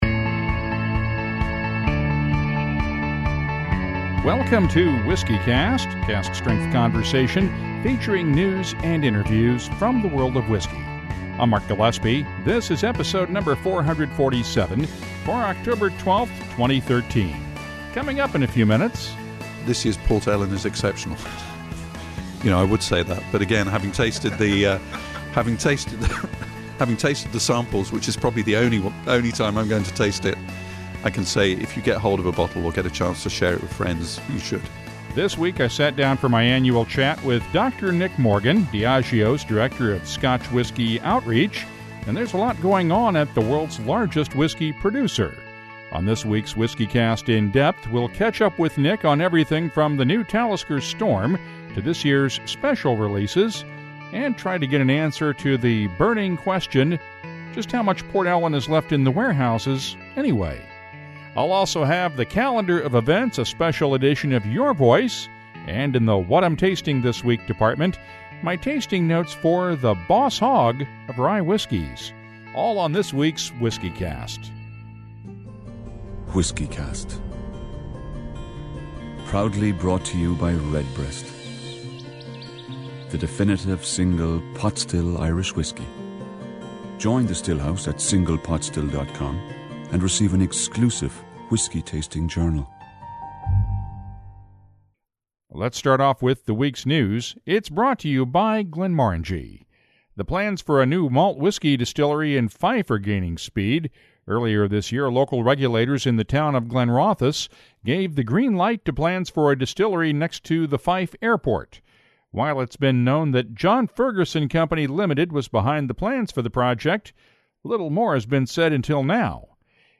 We’ll also hear WhiskyCast listeners venting their frustrations (and singing their praises) in this week’s Your Voice.